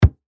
Звуки топота ног
Топнул ногой